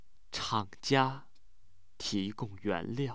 sad